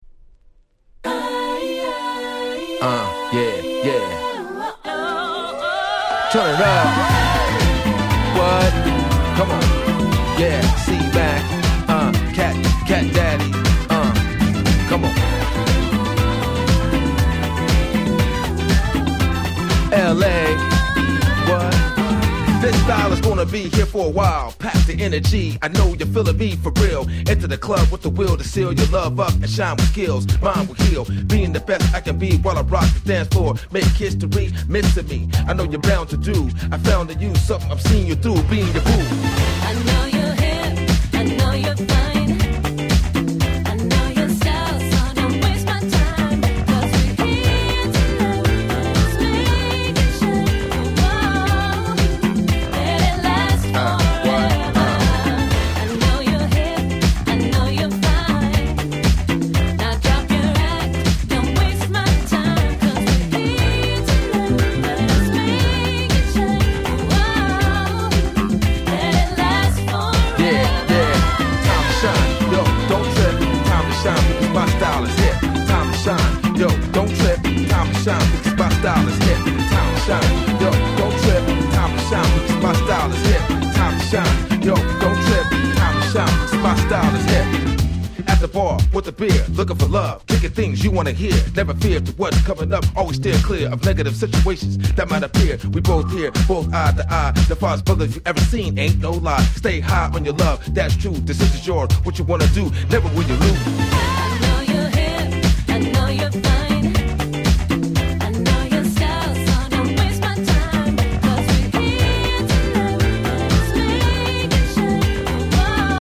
05' NiceキャッチーR&B !!
日本人受け抜群のキャッチーでPopな1曲！
どことなくダンクラ/Disco風味でついつい踊りたくなってしまいます(笑)